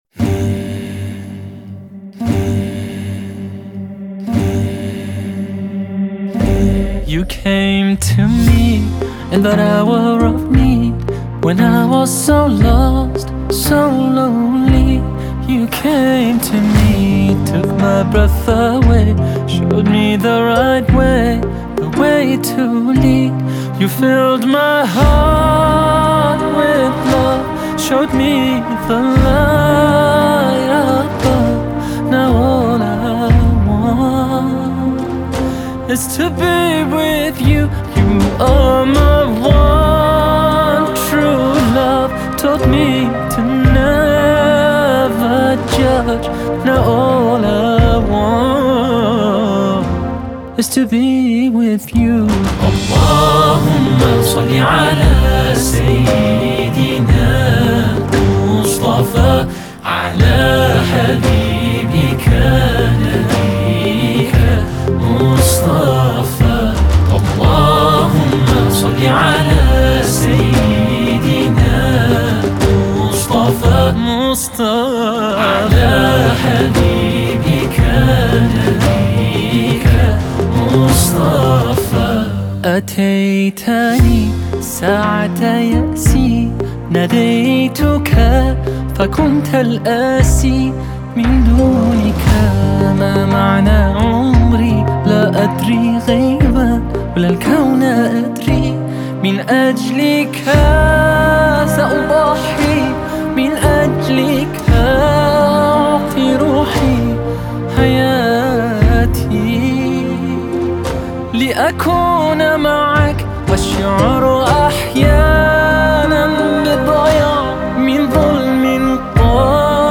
British Muslim singer-songwriter
Please take time and listen to the wonderful Islamic Songs